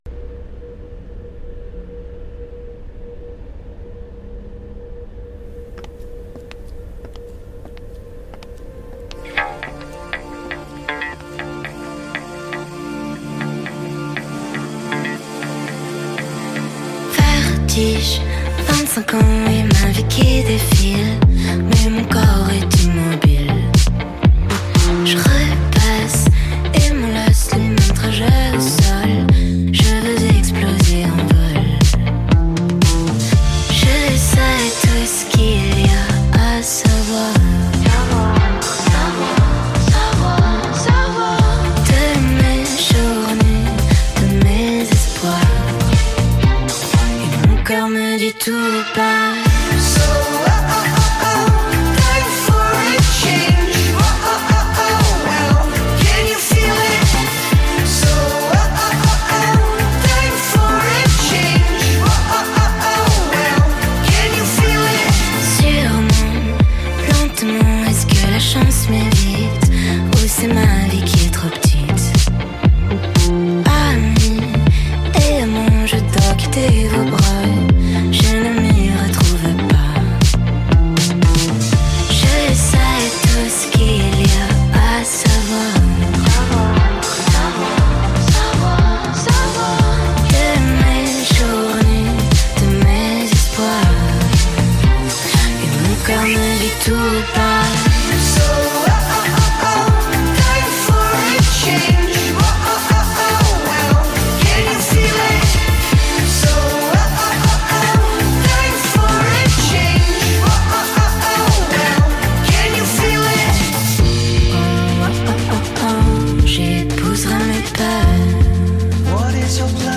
Le single plus tubesque et dansant que jamais